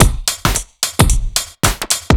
OTG_Kit 4_HeavySwing_110-C.wav